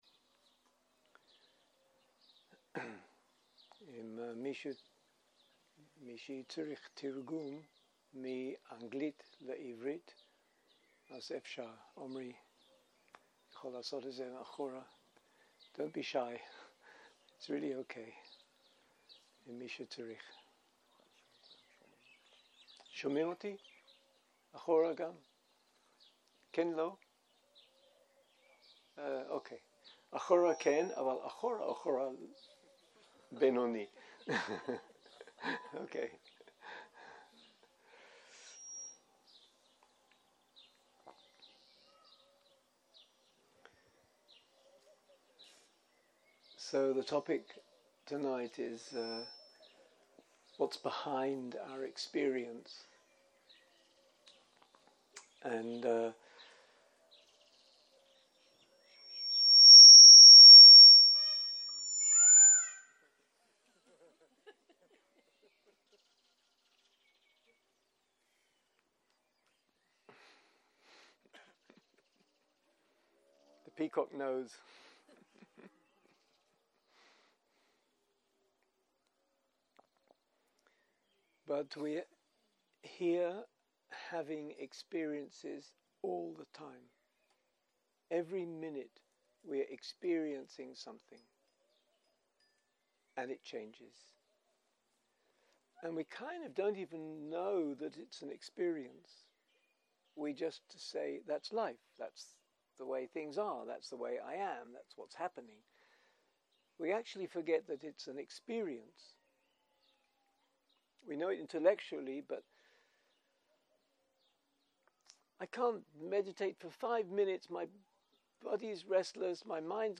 ערב - שיחת דהרמה - Whats behind our experiences
סוג ההקלטה: שיחות דהרמה